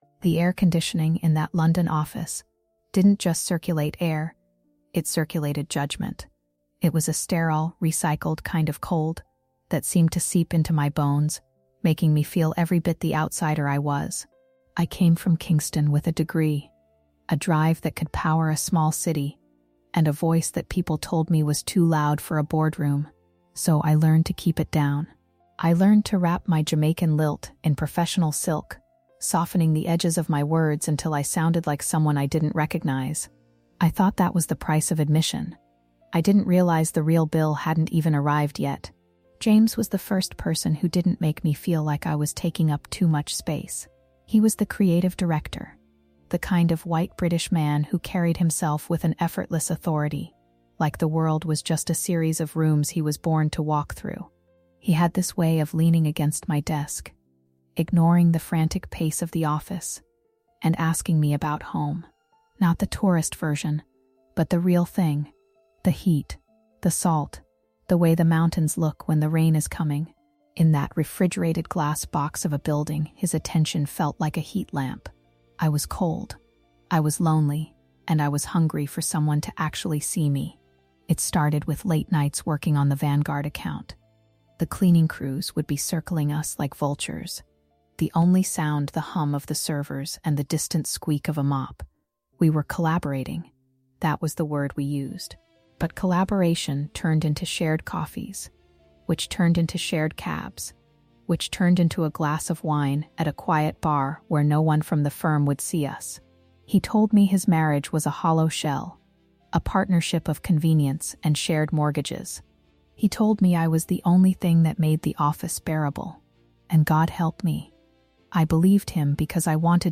This gripping first-person account serves as a cautionary tale about the "negotiable" pieces of our identity we often surrender for a seat at the table.